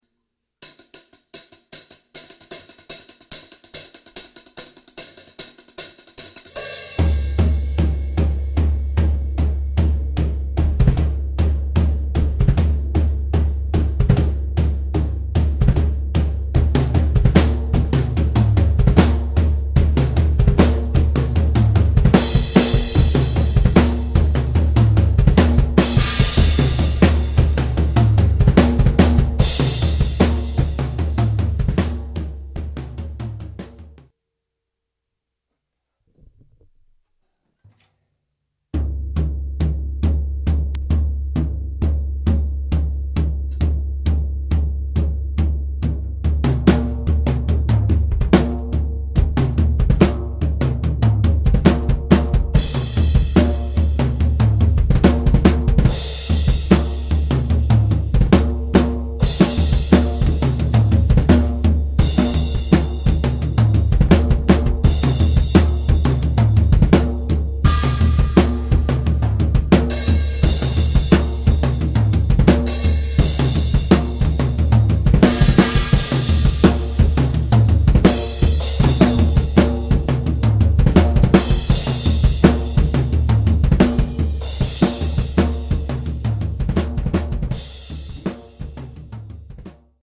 First part of that recording is done without treatment. 2nd part *only* the treatment of the room was changed.
Also, yeah... I did that test precisely to actually see if foam would make any difference, so I set up my drums and mics, pushed the record button, played for a bit, then spent a couple hours putting up the foam - didn't touch mics, drums, or software, and when I was done, sat back down and played again for a few seconds.
The sound in the second part is more muffled.....or rather, the decay and reverberation is much less.